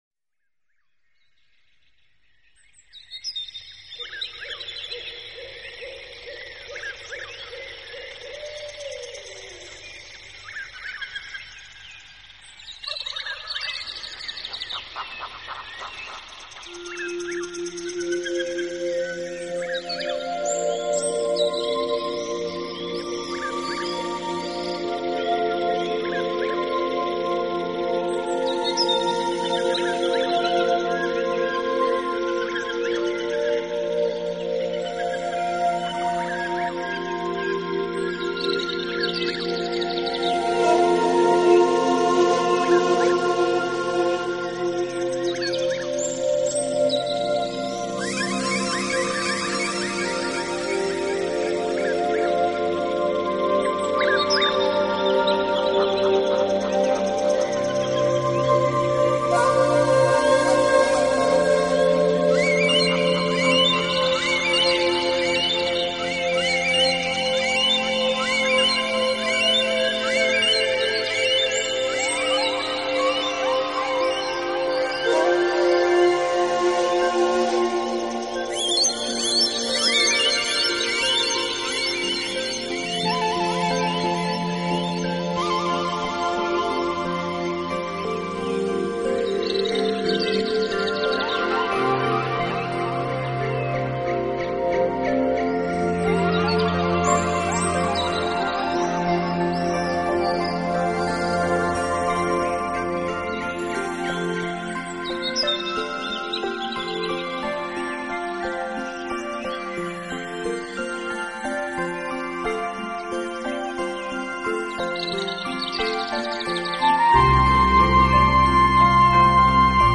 空 气中沸腾的热力、高照的艳阳、碧蓝的海水、热烈的情绪都在作曲家富有激情的 韵律中得到尽情的释放。